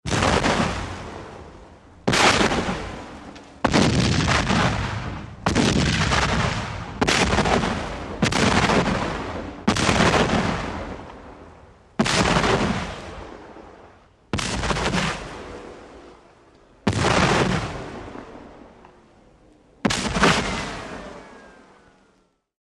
WEAPONS - CANNON CIVIL WAR CANNON: EXT: Model 1841 bronze cannons, multiple shots.